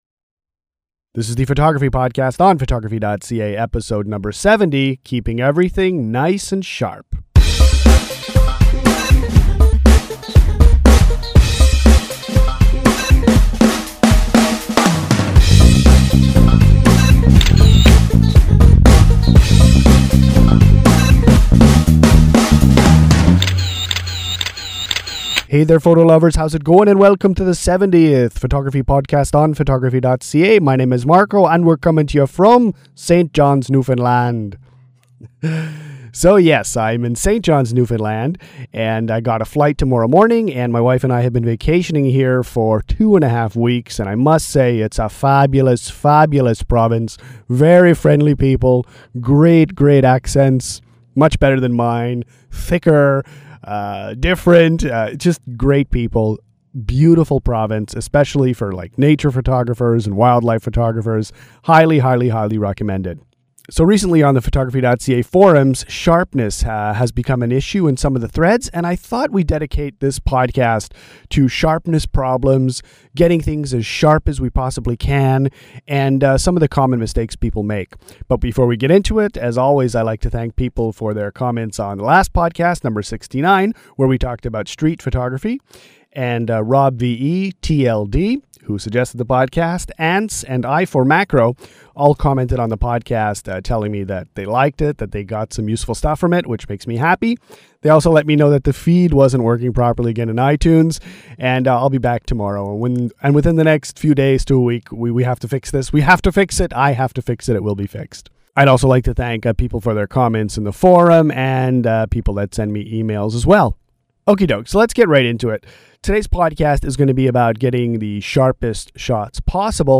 Photography podcast #116 features an interview with German landscape photographer